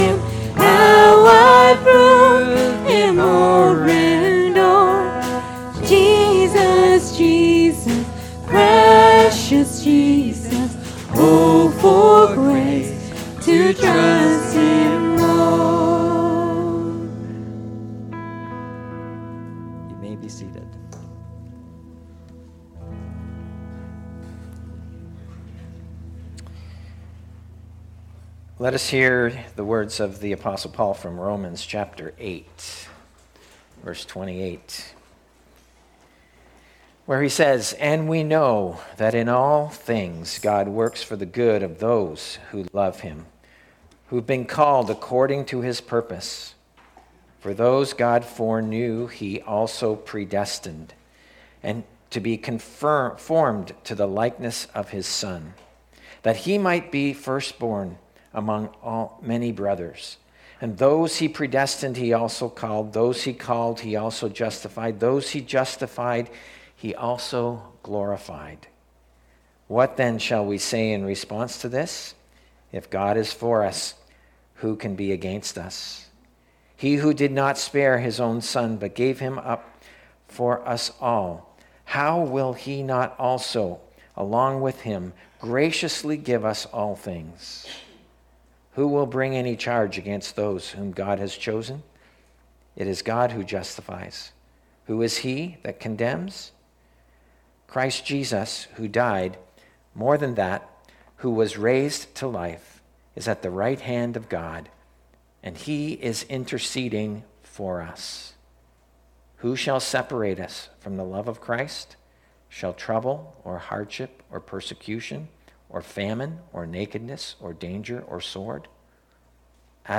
Sermons | Edmison Heights Baptist